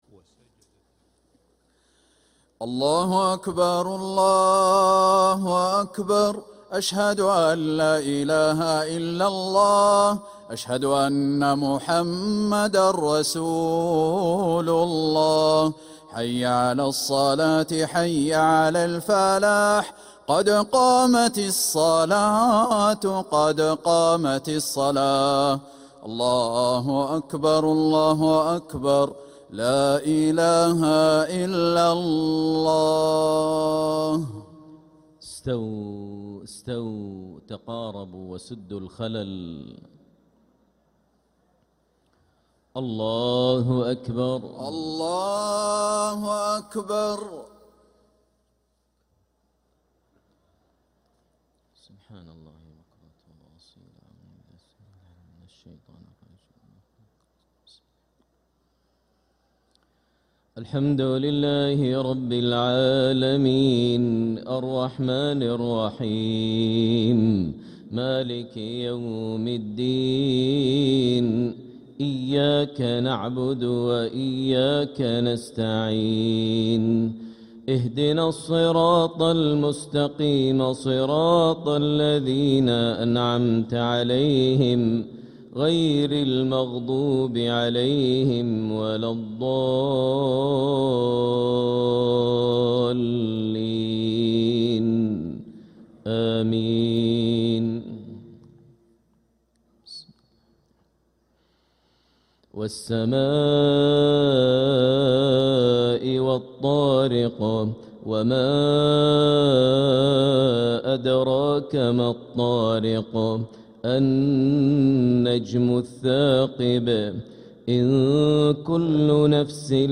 Makkah Maghrib - 27th March 2026
Makkah Maghrib (Suras Tariq & Shams) Sheikh Mu'ayqali Download 128kbps Audio